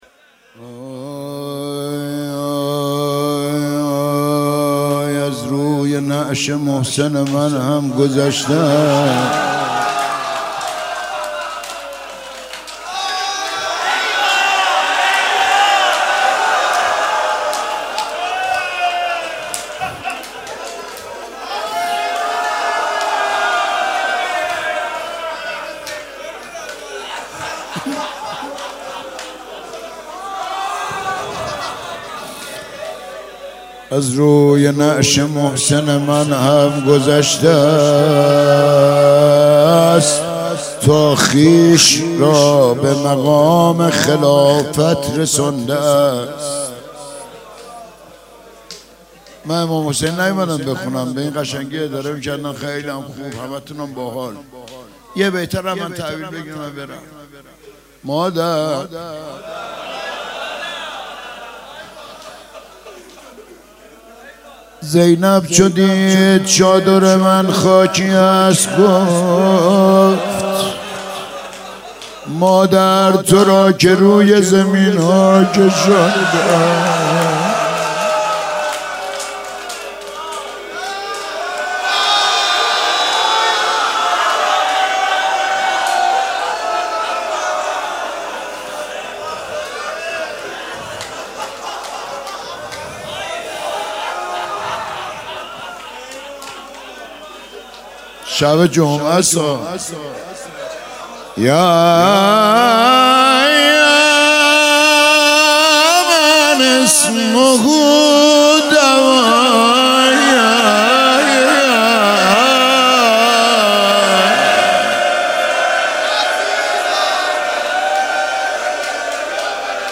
5 بهمن 96 - هیئت الزهرا سلام الله علیها - روضه - از روی نعش محسن من